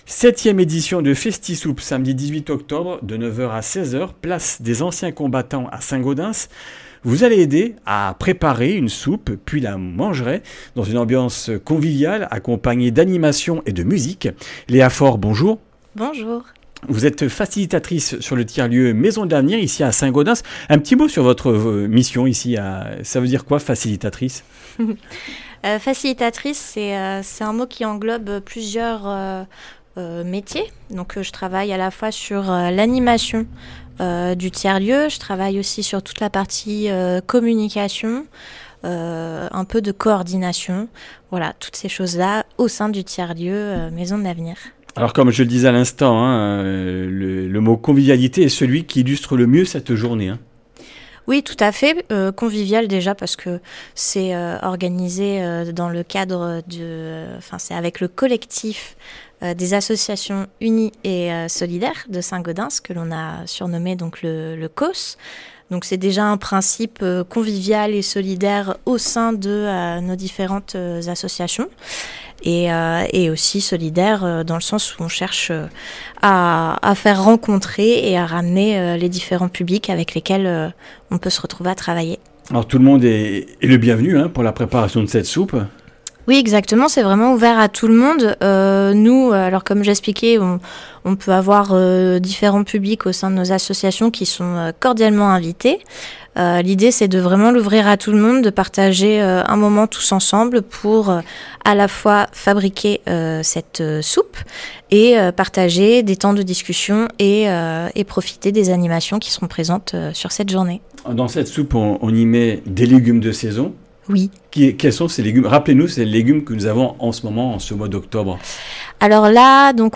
Comminges Interviews du 15 oct.
Une émission présentée par